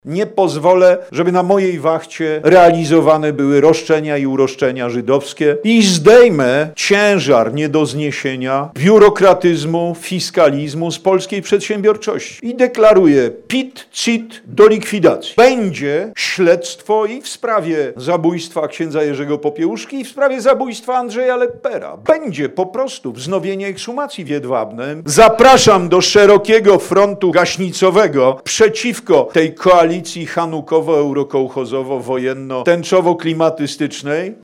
Na wieczornym spotkaniu w Koszalinie polityk przekonywał do swojej kandydatury i zapowiedział zniesienie podatku PIT I CIT.